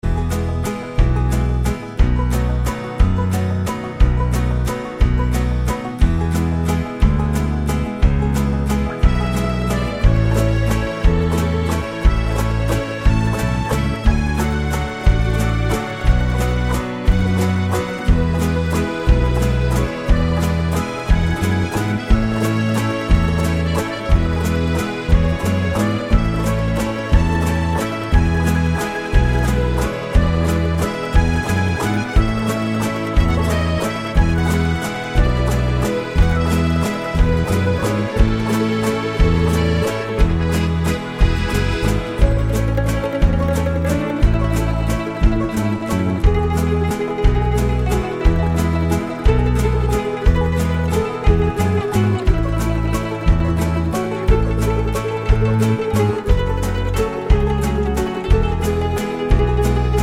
no Backing Vocals Irish 4:55 Buy £1.50